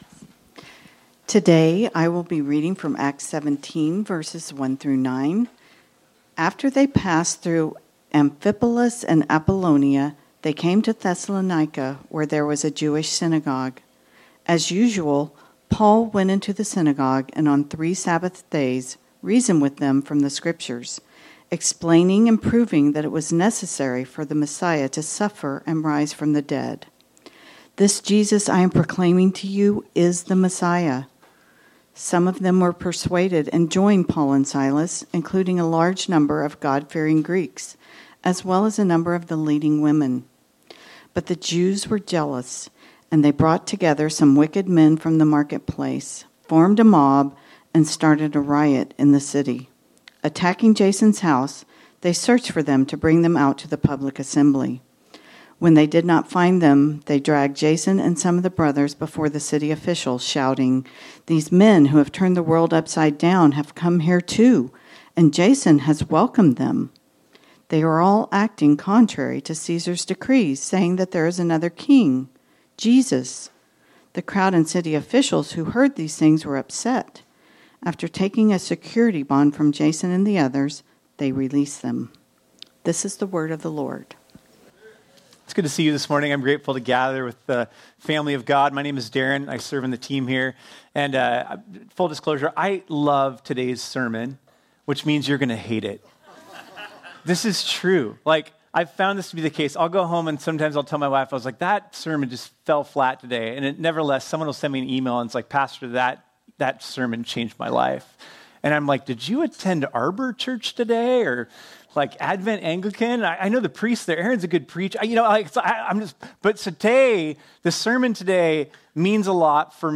This sermon was originally preached on Sunday, June 1 2025.